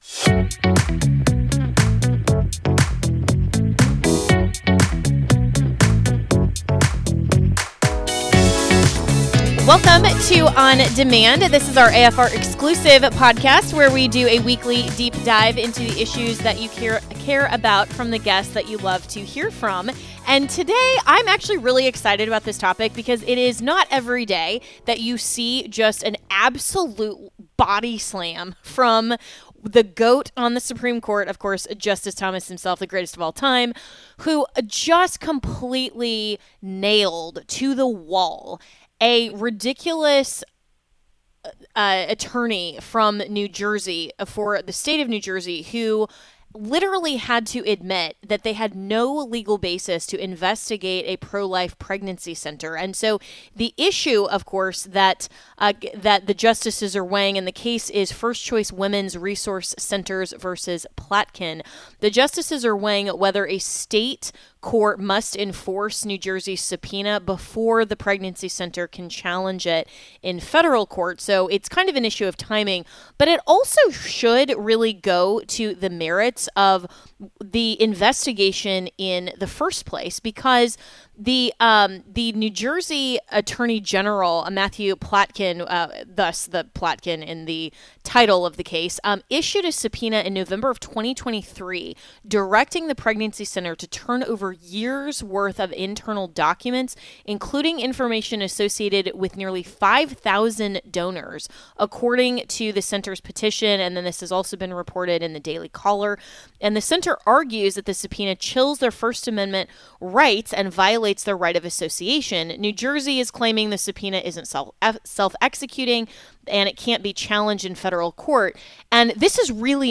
Father Frank Pavone, Founder of Priests For Life, joined the podcast to discuss the oral arguments before the Supreme Court over NJ targeting Pro-Life Groups